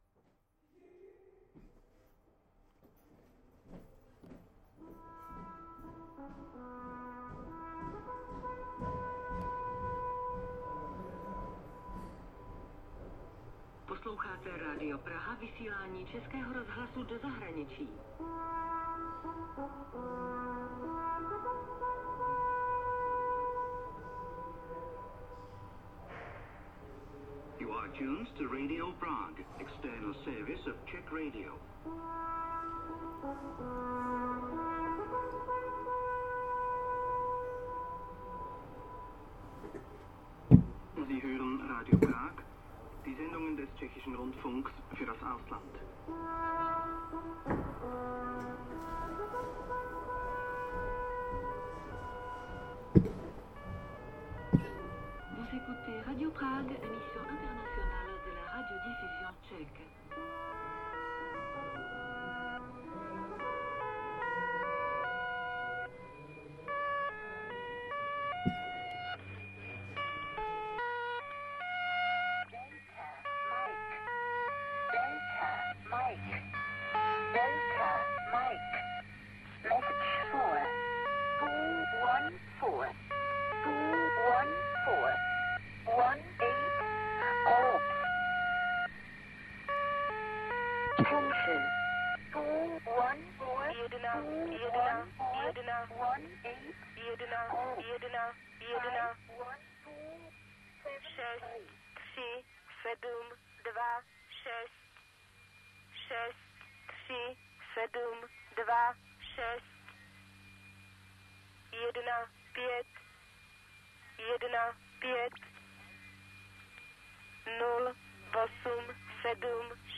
field recordings, sound art, radio, sound walks
Tagy: hudba lidé archiv radio
Odsud prý vysílalo na krátkých vlnách jedno z tajemných "numerických" rádií, nazývané podle jakési šifry "OLX MOI".
Deklamování nekonečných řetezců čístel, čtených často ženským hlasem, si mohli posluchači naladit i na běžných rádiopřijímačích. Kódy byly určeny emisarům rozvědky patrně na Západě.